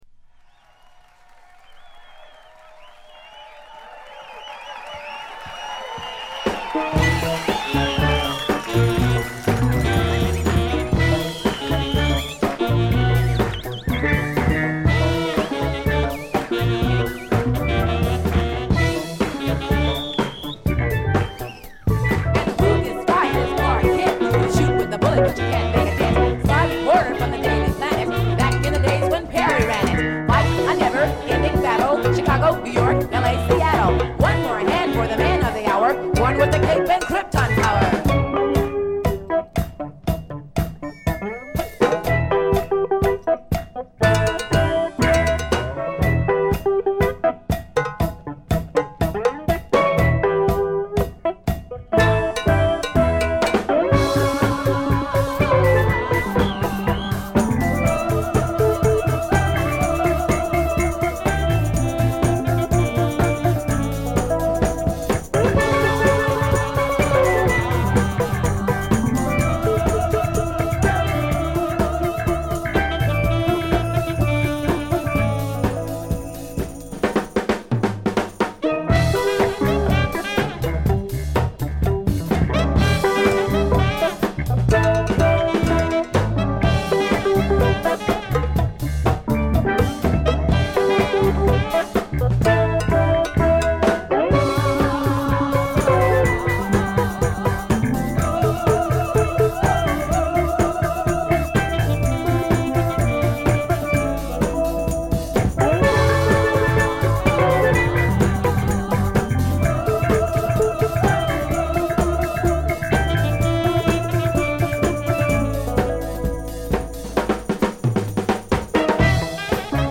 ほとんどノイズ感無し。
試聴曲は現品からの取り込み音源です。
electric guitar, piano, lead vocals